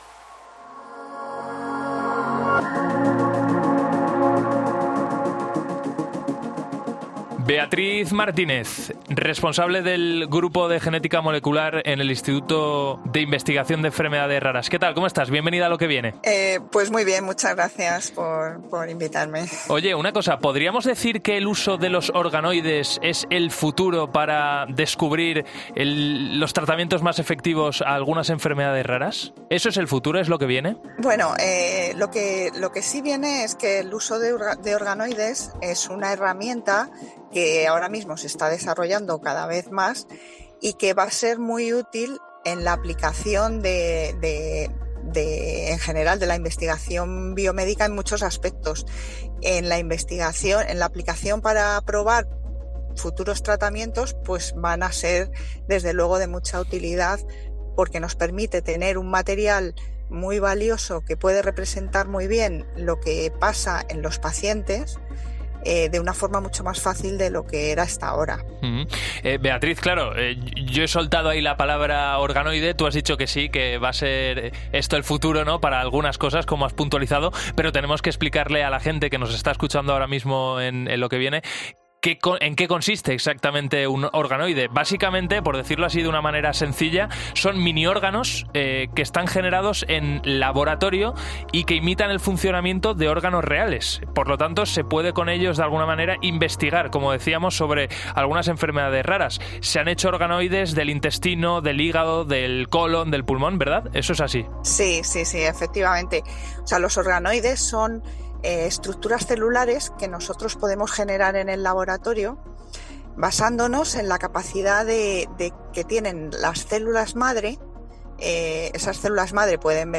En 'Lo que viene' hablamos con una experta en el uso y la investigación de estas estructuras celulares -crecidas en laboratorio- que imitan a órganos reales pero en pequeño tamaño